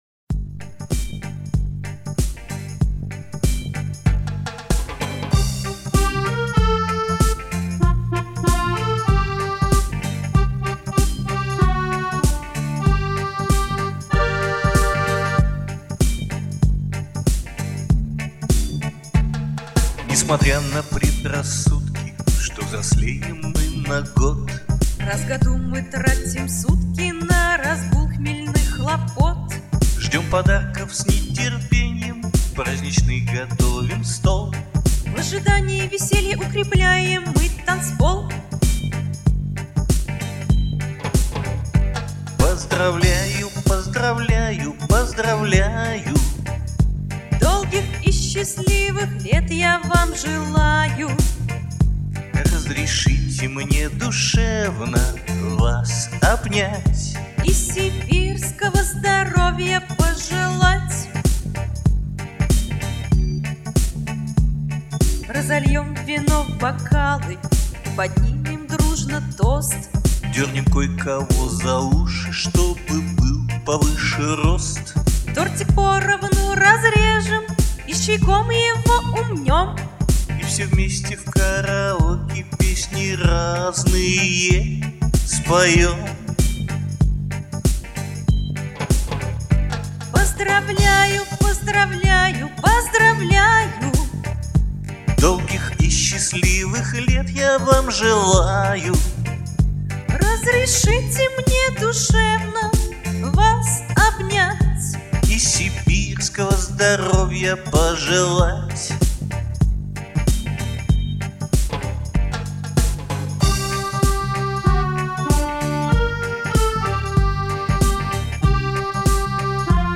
Русский поп-шансон